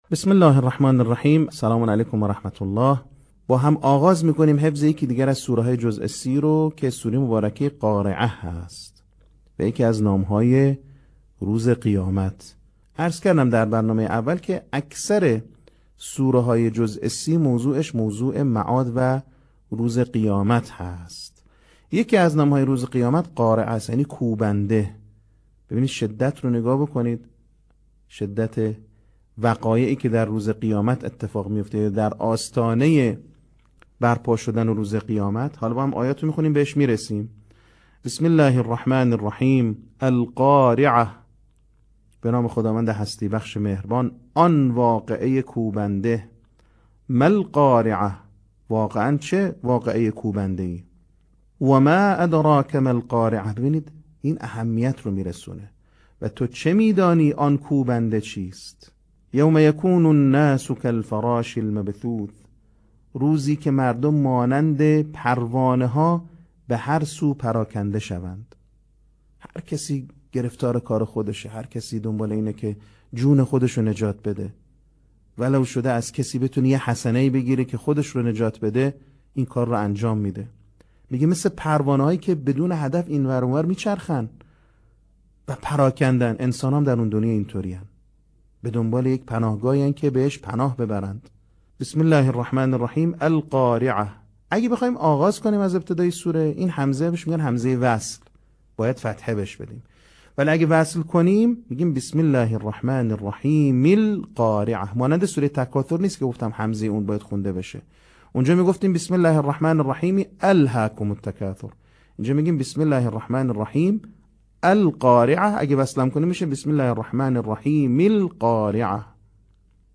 صوت | آموزش حفظ سوره قارعه